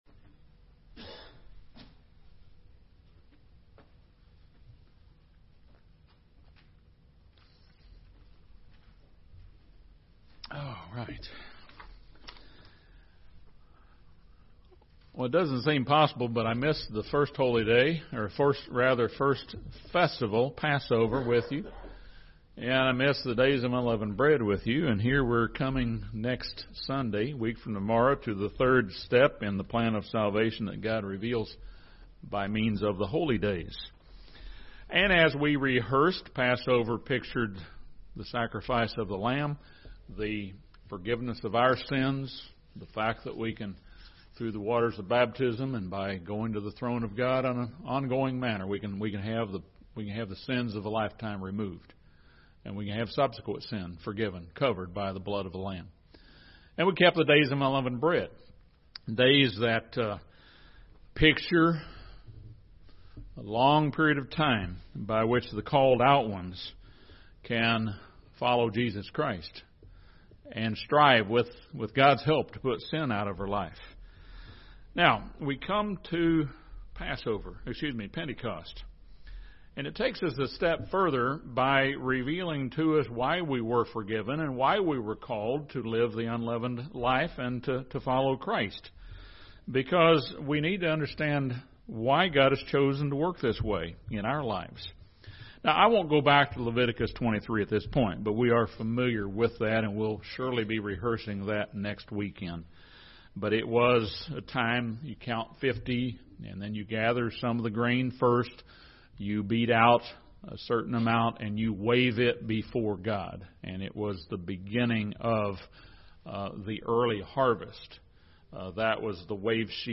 Romans 12 and 1 Corinthians 12 list a variety of gifts of the Holy Spirit. This sermon discusses the gifts of wisdom, knowledge, faith, healings, miracles, prophecy, discernment, service, encouragement, giving and showing mercy.